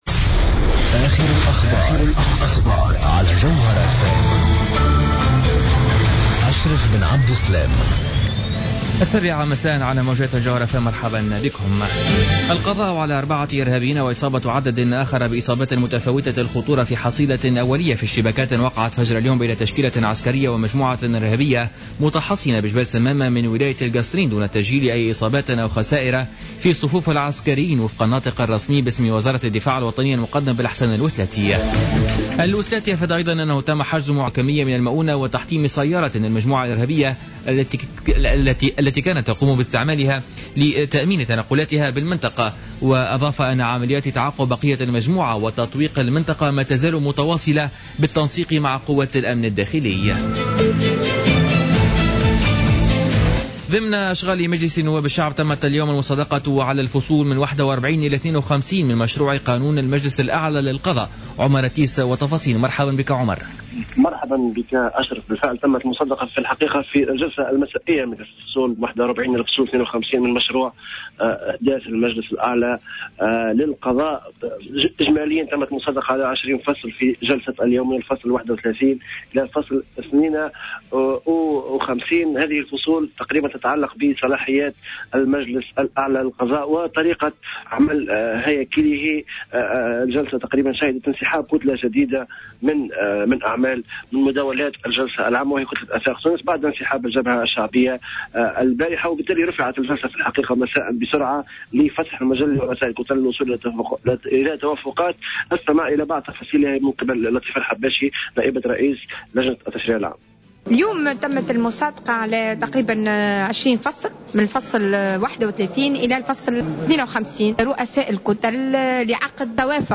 نشرة أخبار السابعة مساء ليوم الخميس 14 ماي 2015